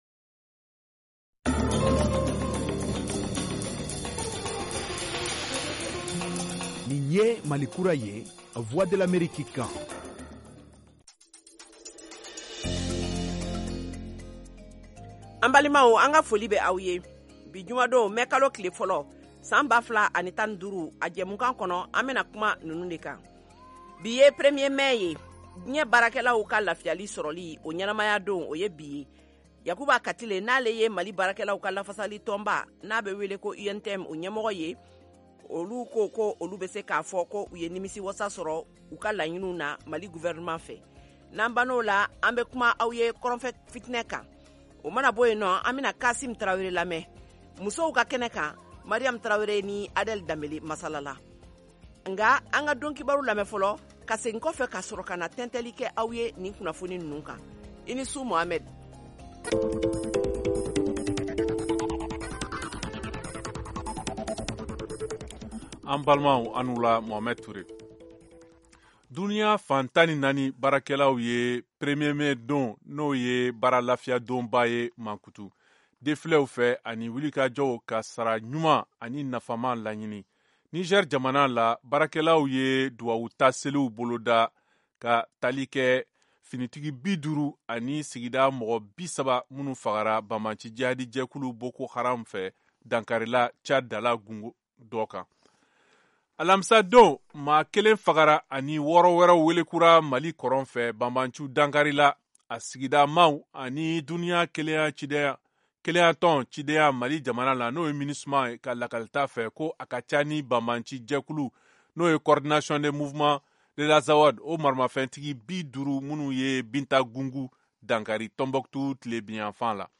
Emission quotidienne en langue bambara
en direct de Washington, DC, aux USA